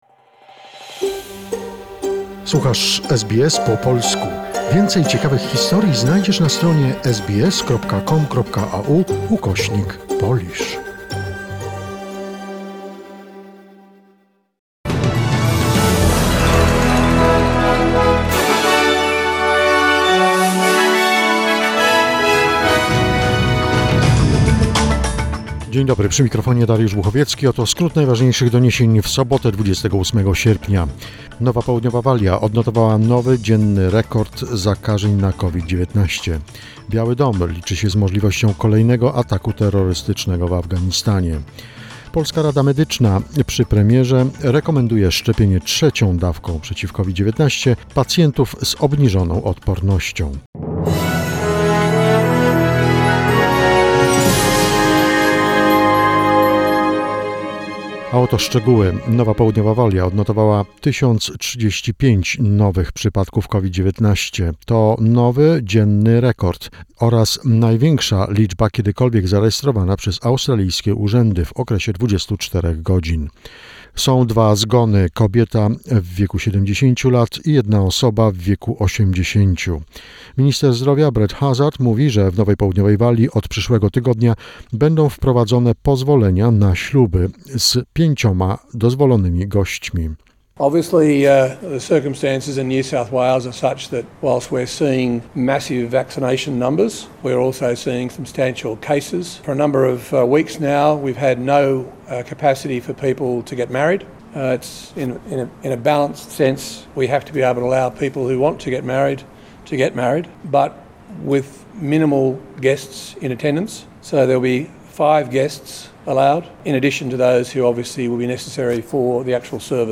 SBS News in Polish, 28 August 2021